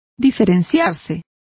Complete with pronunciation of the translation of differ.